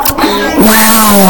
Wow Sound Button - Free Download & Play
Reactions Soundboard6 views